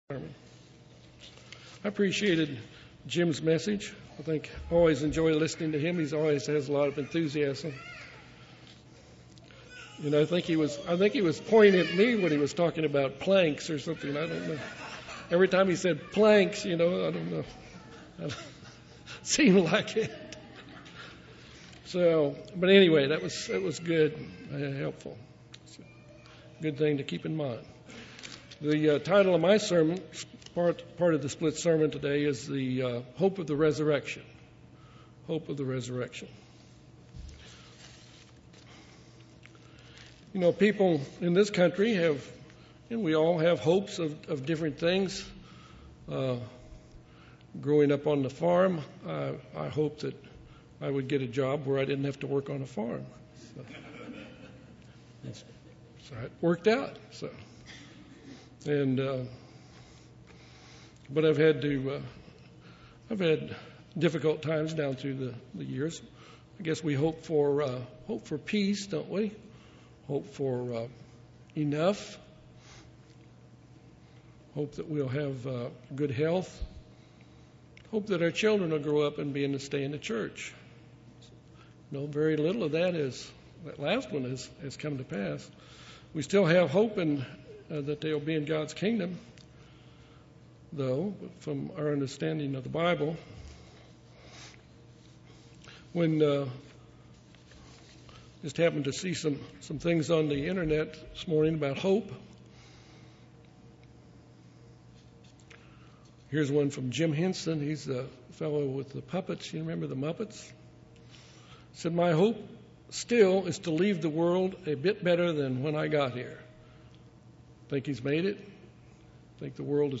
Given in Little Rock, AR
UCG Sermon Studying the bible?